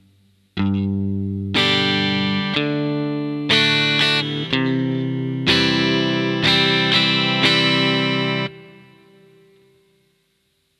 The chords are simple (G, D and C) so that you can focus on the strumming, picking and rhythm approaches.
Exercise no.2 doesn’t change any of the rhythms but does introduce a Country-style approach of starting each chord with it’s root note (eg. the first note picked in bar 1 is is ‘G’).